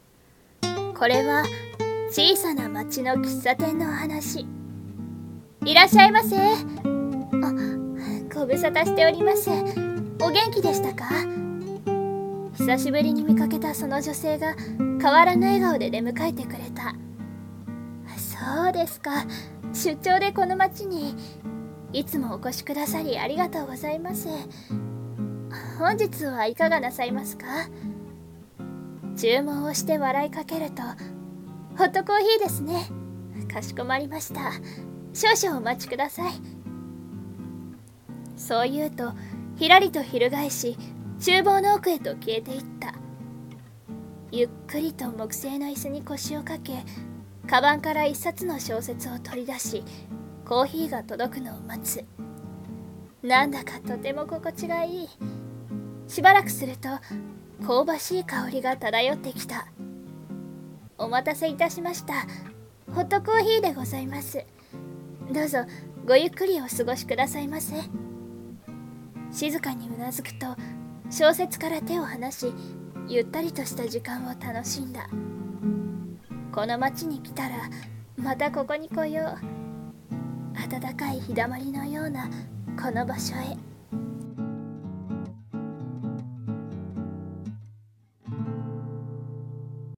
【声劇台本】小さな町の喫茶店。